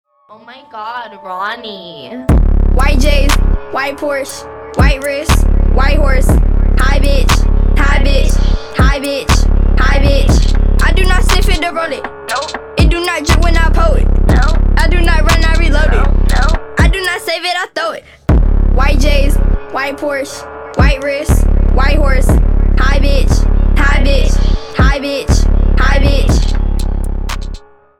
• Качество: 320, Stereo
мощные басы
Trap
качающие
Rap
женский рэп
злые